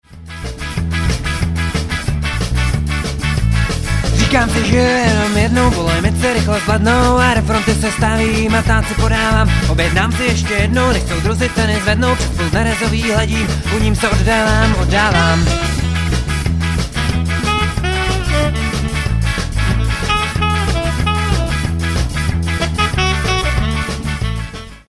Nahráno na jaře 2005 v Říčanech.